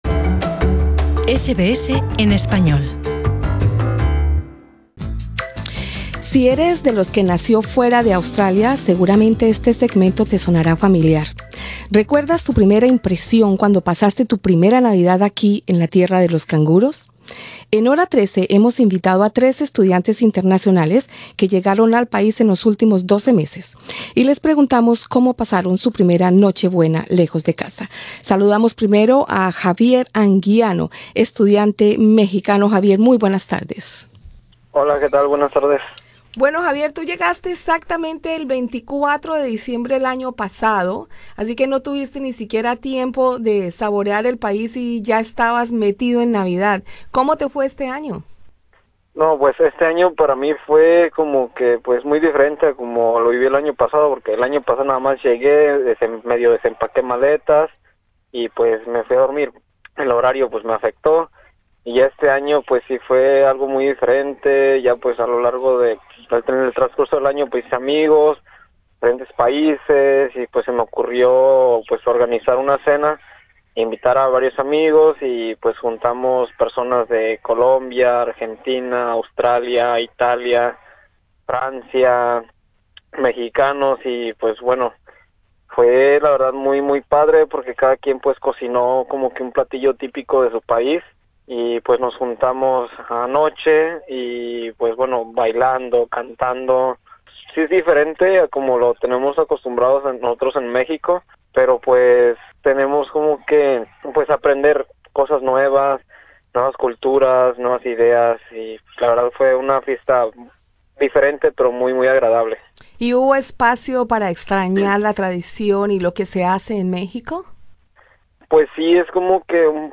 Escucha en nuestro podcast la entrevista a tres estudiantes internacionales que llegaron a Australia en los últimos 12 meses, y quienes nos cuentan cómo fue su primera navidad tan lejos de casa.